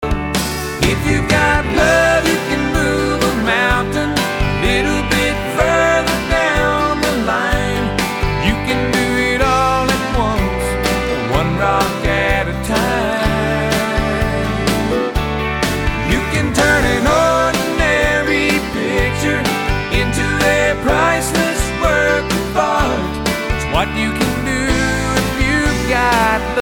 Господа, как сделать такие бэки?
Как добиться звучания таких бэков?